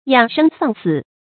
养生丧死 yǎng shēng sàng sǐ
养生丧死发音